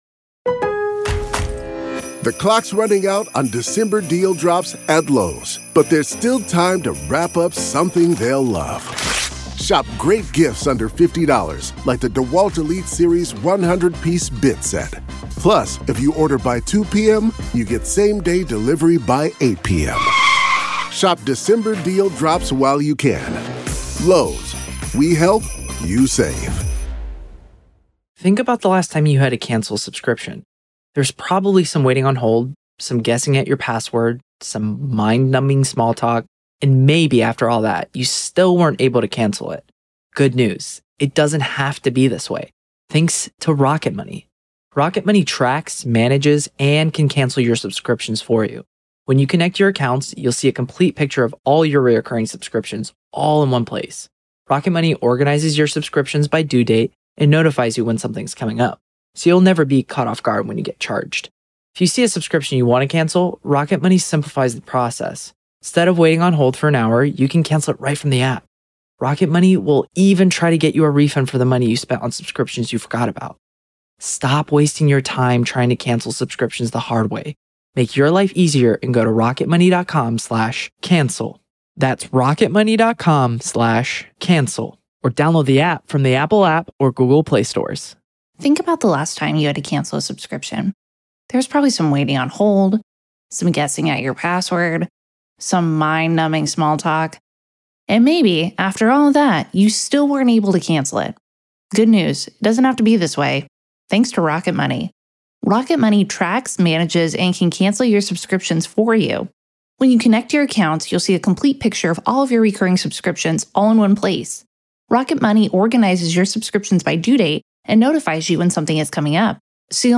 LIVE COURTROOM COVERAGE — NO COMMENTARY
This series provides unfiltered access to the testimony, exhibits, expert witnesses, and courtroom decisions as they happen. There is no editorializing, no added narration, and no commentary — just the court, the attorneys, the witnesses, and the judge.